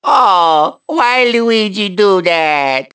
One of Luigi's voice clips in Mario Kart 7